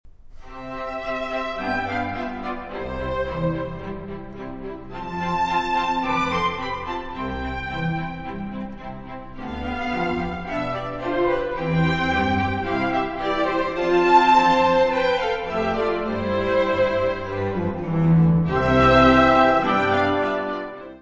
Music Category/Genre:  Classical Music
Andante.